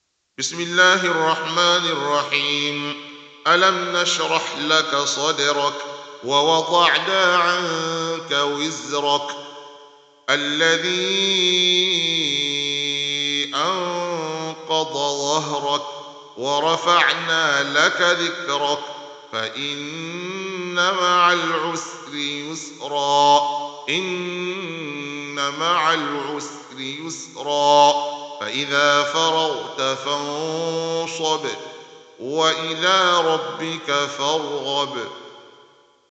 Lecture